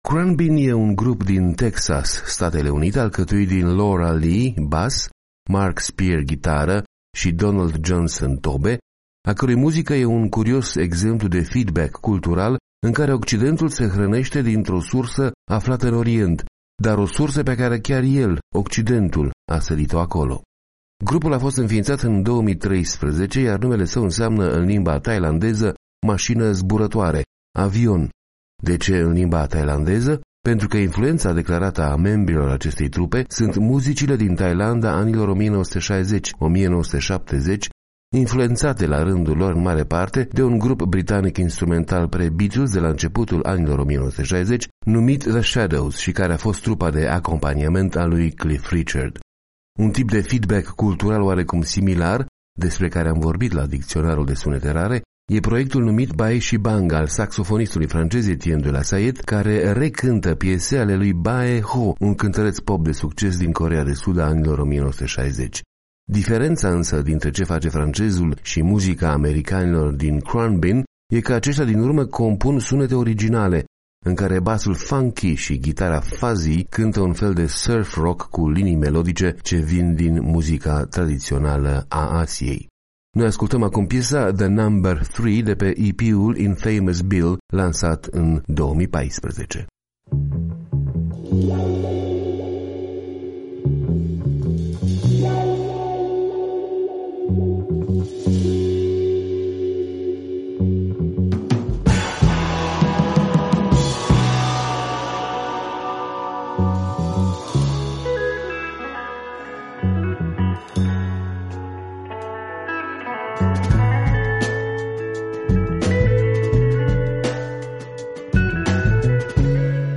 ghitară